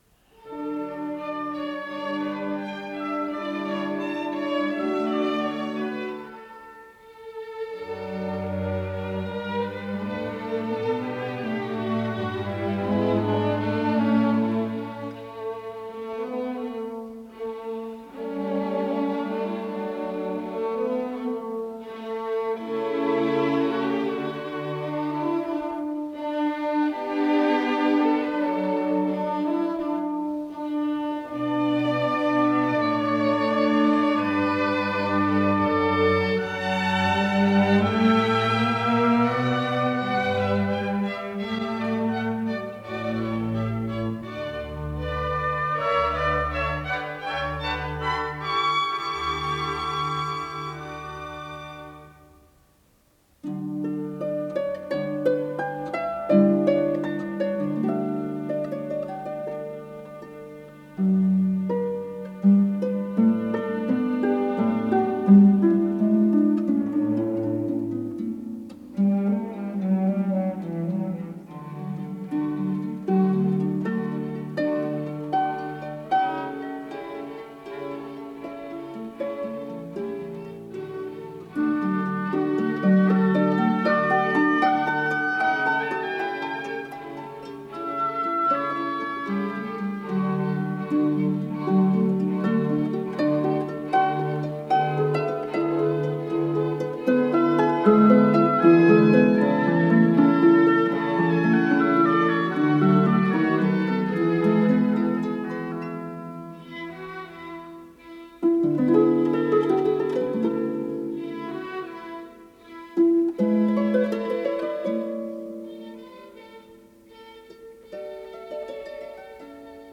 ПодзаголовокОригинал для клавесина с оркестром, ля мажор, соч. 1779 г.
ИсполнителиОльга Эрдели - Арфа
АккомпаниментБольшой симфонический оркестр Всесоюзного радио и телевидения
Художественный руководитель и дирижёр - Геннадий Рождественский
Скорость ленты38 см/с
ВариантДубль моно